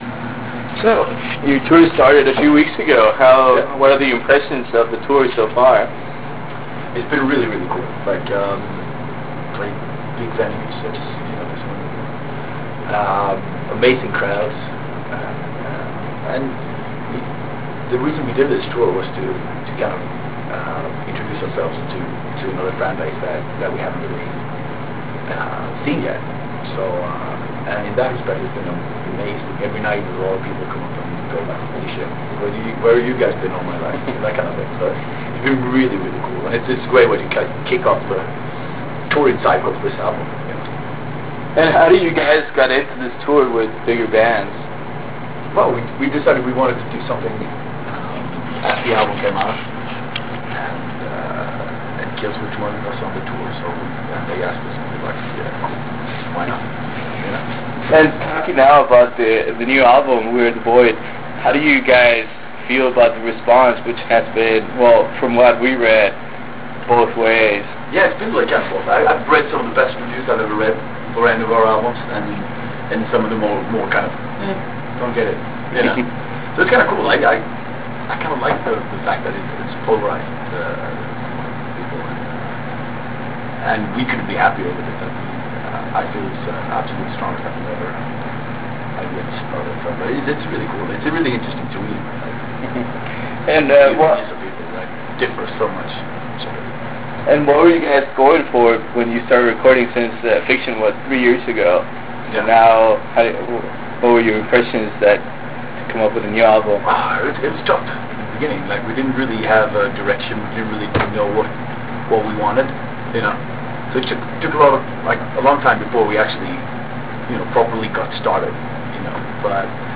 Interview with Dark Tranquillity - Mikael Stanne
During our last concert road-trip, we had the opportunity to sit down with Mikael Stanne. During our conversation with Dark Tranquillity's frontman, we talked about their current 7 week tour with KSE and TDWP, we also discussed in detail their latest album "We Are The Void".